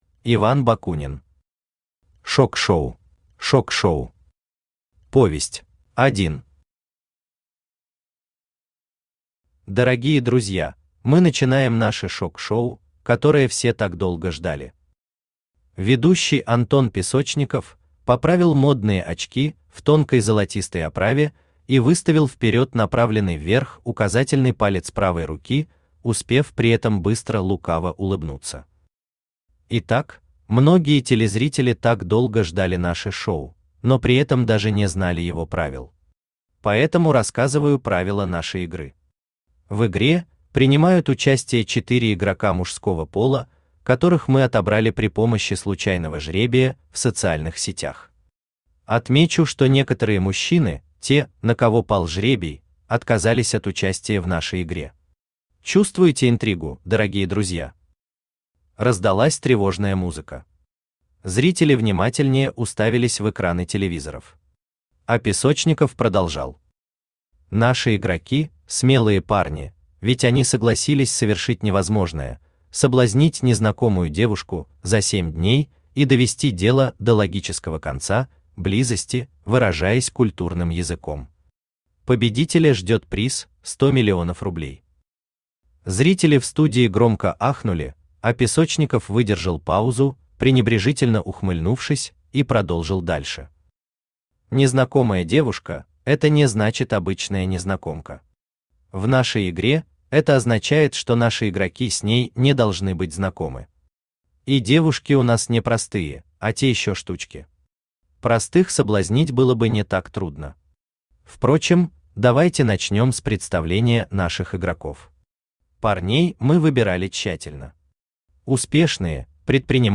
Аудиокнига Шок-шоу | Библиотека аудиокниг
Aудиокнига Шок-шоу Автор Иван Бакунин Читает аудиокнигу Авточтец ЛитРес.